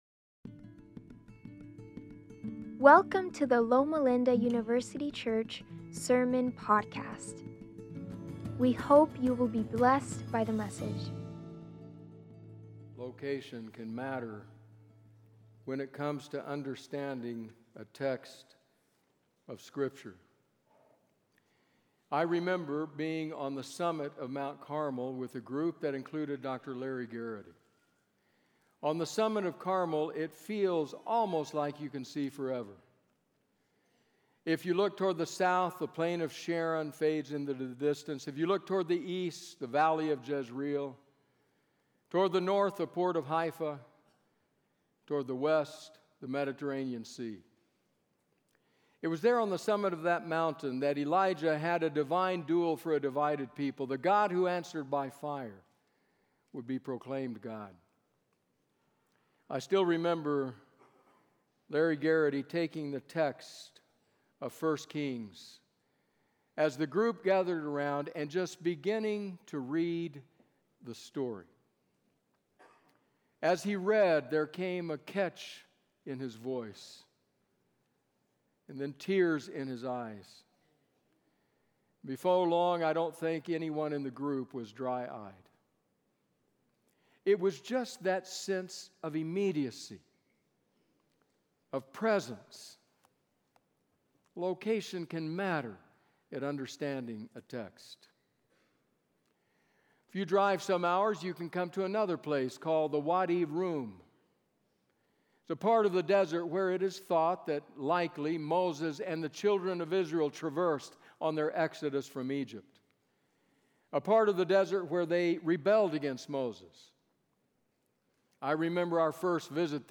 February 15, 2025: Comfort and Hope - LLUC Sermon Podcast